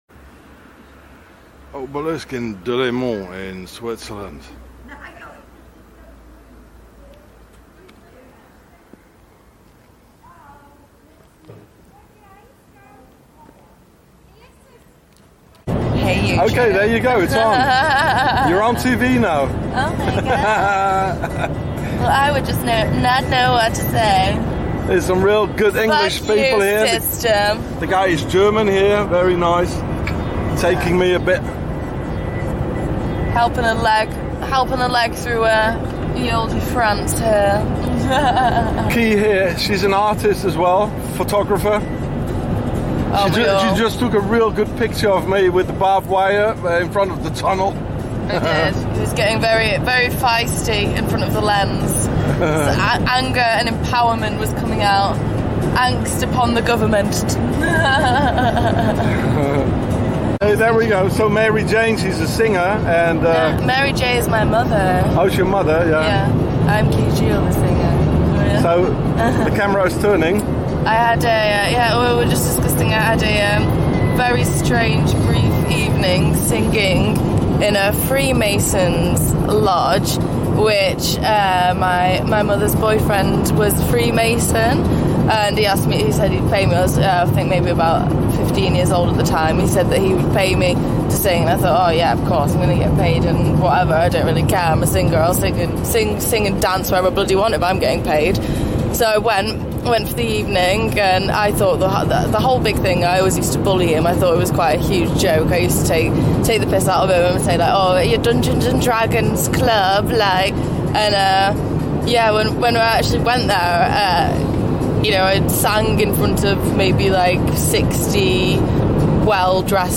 and she took me hitchhiking while talking about her experience and performance in a freemason lodge for a throne and 60 well dressed men with swords.